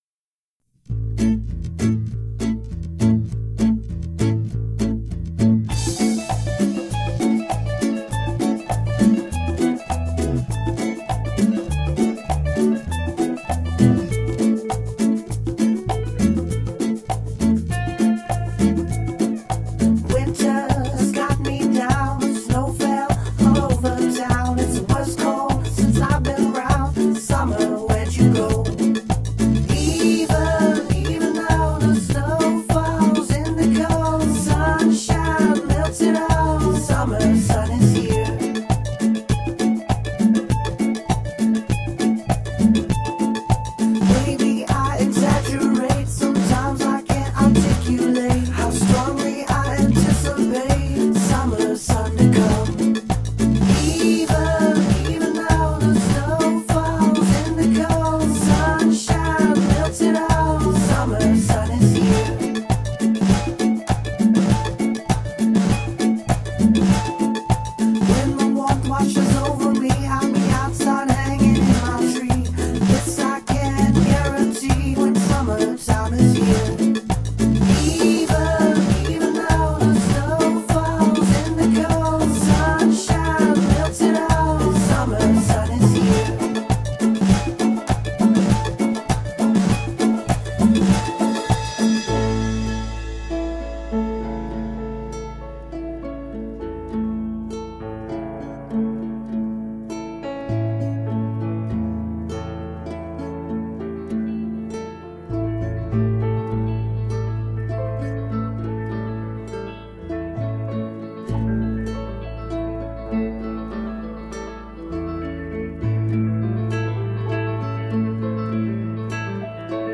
Uptempo, happy, banjo-driven acoustic folk pop.
Features a lot of ukulele, banjo, and acoustic guitars.
Tagged as: Alt Rock, Folk, Folk-Rock, Folk